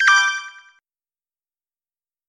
Короткий звук при включении устройства